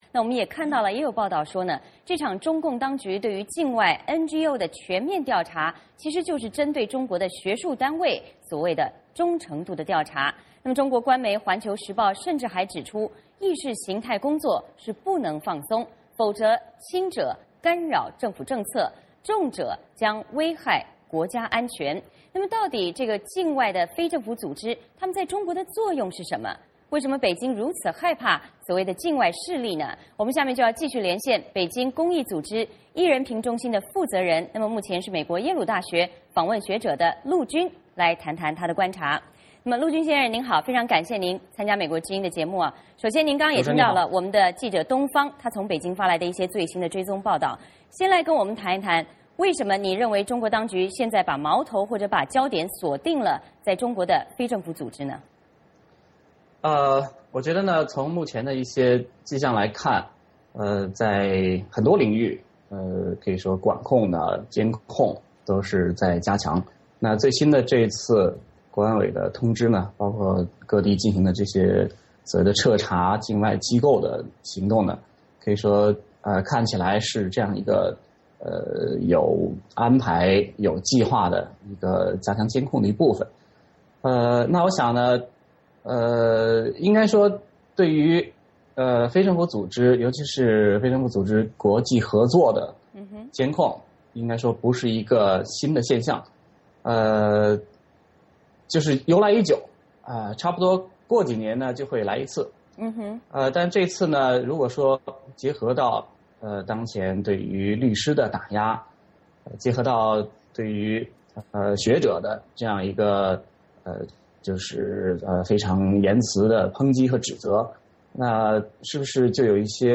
VOA连线：中国清查NGO，背后动机复杂